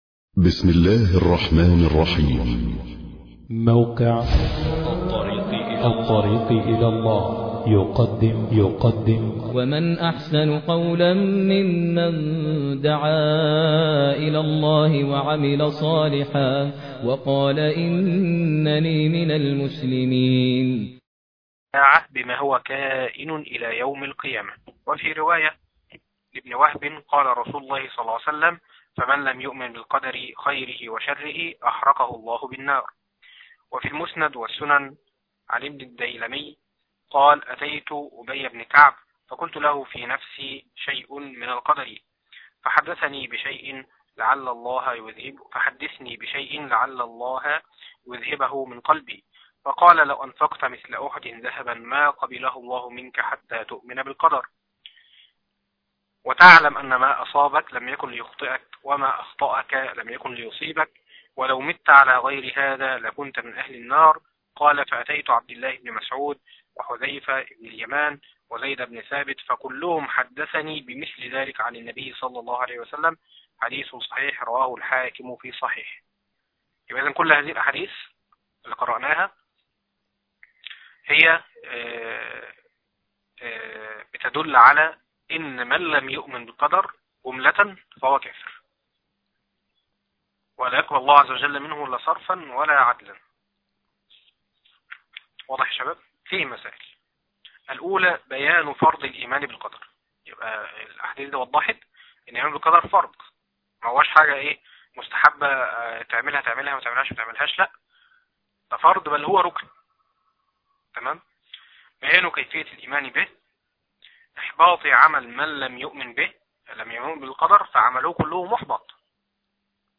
عنوان المادة الدرس الثالث عشر - دورة شرح كتاب التوحيد تاريخ التحميل الخميس 10 يناير 2013 مـ حجم المادة 15.99 ميجا بايت عدد الزيارات 1,087 زيارة عدد مرات الحفظ 371 مرة إستماع المادة حفظ المادة اضف تعليقك أرسل لصديق